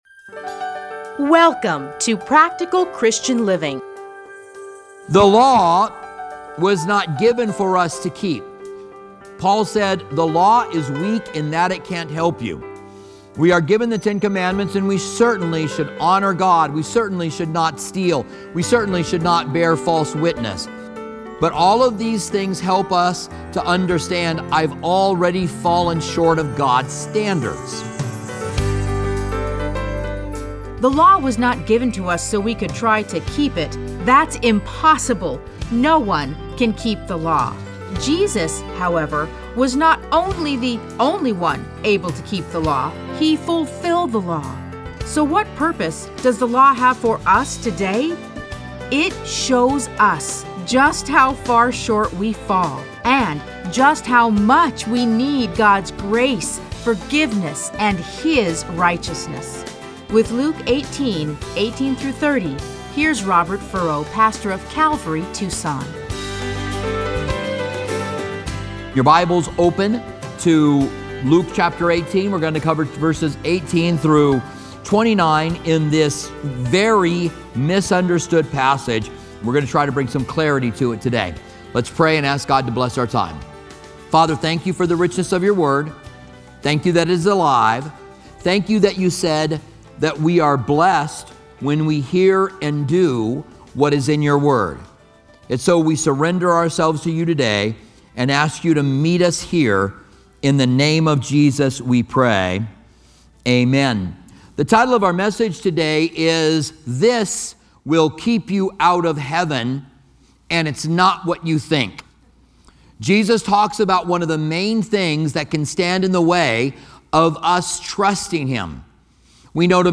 Listen to a teaching from Luke 18:18-30.